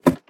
should be correct audio levels.
ladder2.ogg